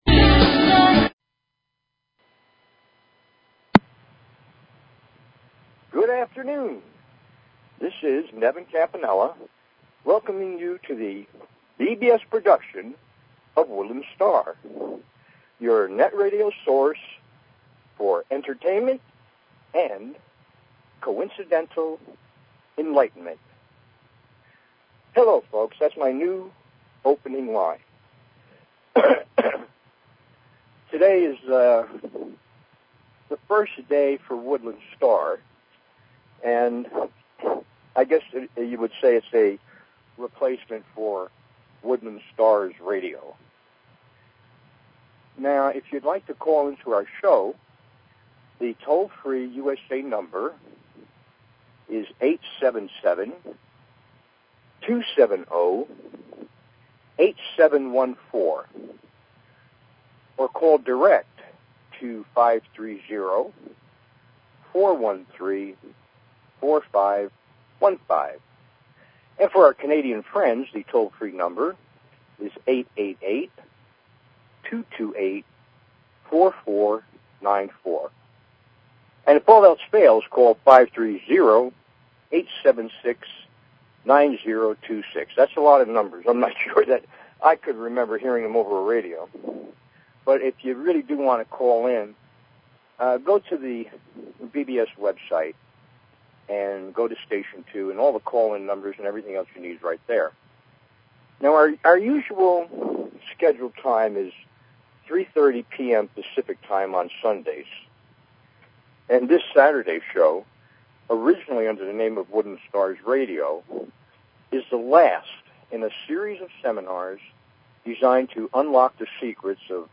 Talk Show Episode, Audio Podcast, Woodland_Stars_Radio and Courtesy of BBS Radio on , show guests , about , categorized as